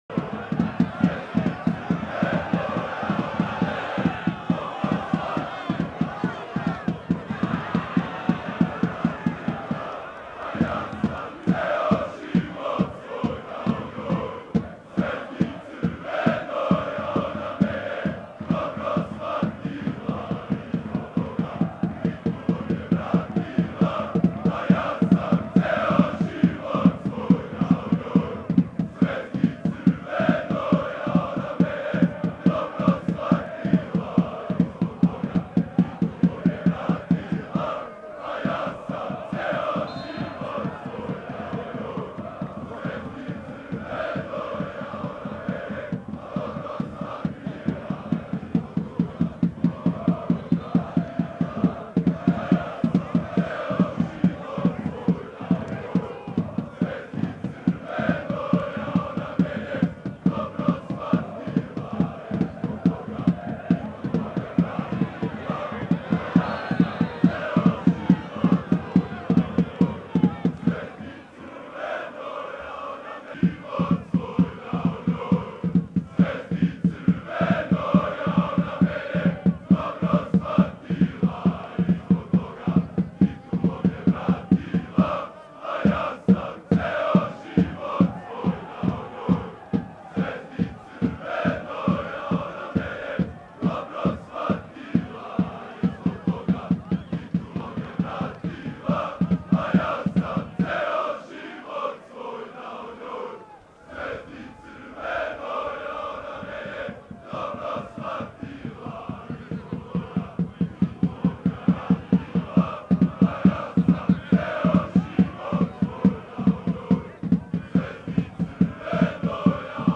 Аудио са текме:
rad_navijanje.wma